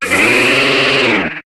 Cri de Chevroum dans Pokémon HOME.